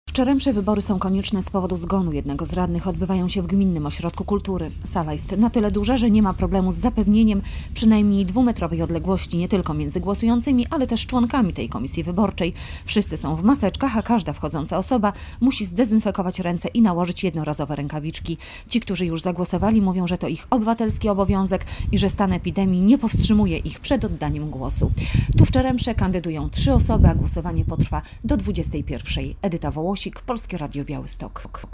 Jak przebiegają wybory w Czeremsze? Relacja